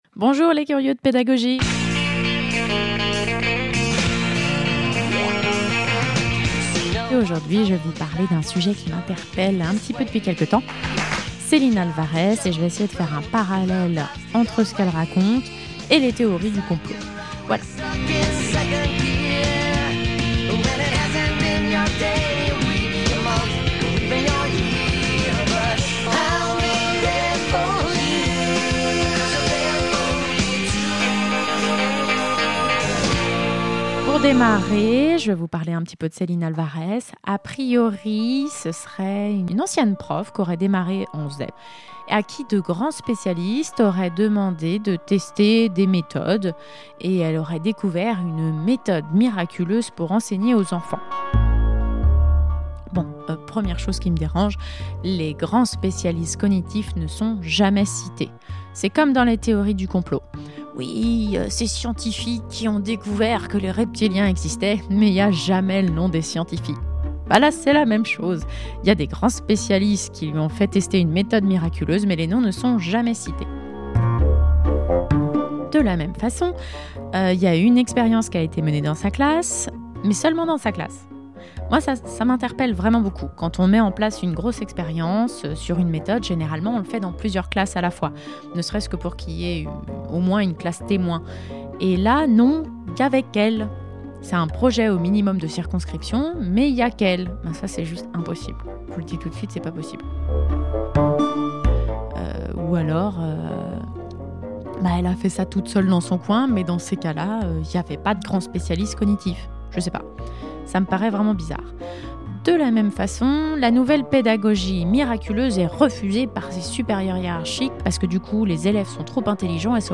Alors juste pour savoir, pourquoi le générique de friends pour ton générique (désolé je ne me souviens plus du nom originelle de la chanson)